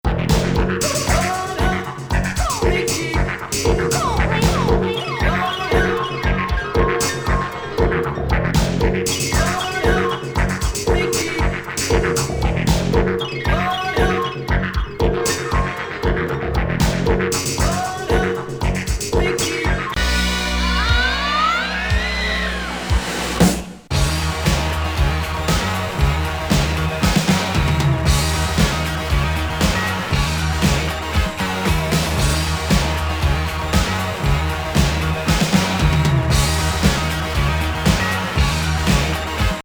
自身のヒット作をエディット＆コラージュした怪作！